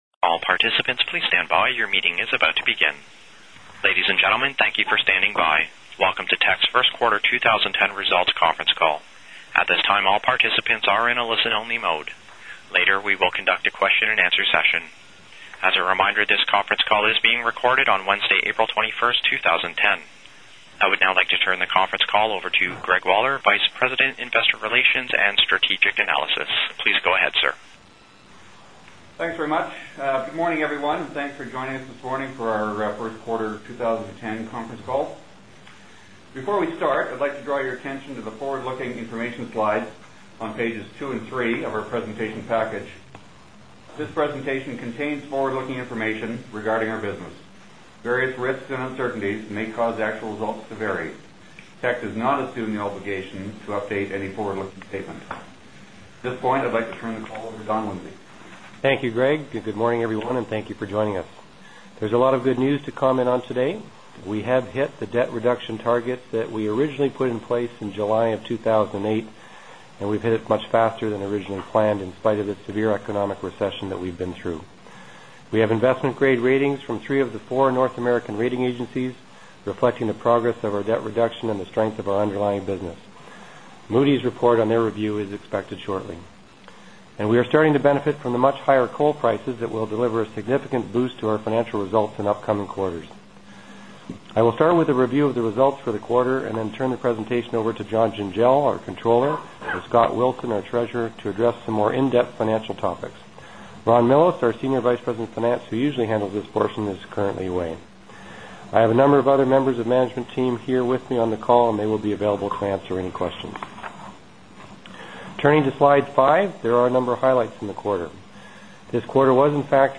Q1 2010 Financial Report Conference Call Audio File